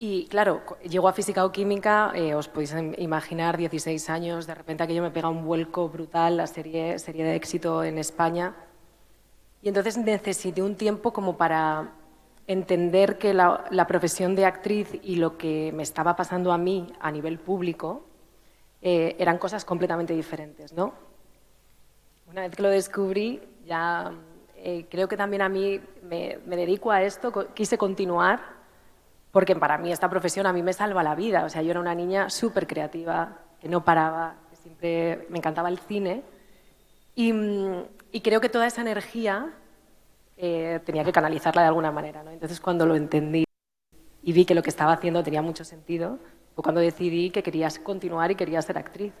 Por segundo día consecutivo, el Patio de Luces de la Diputación Provincial ha vuelto a registrar un lleno absoluto en la segunda mesa redonda ‘Encuentros sobre cine y televisión’.
En esta segunda cita han participado las actrices Andrea Duro, Itziar Miranda y Verónica Sánchez y el actor Tamar Novas.
15-11_fical_mesa_andrea_duro.mp3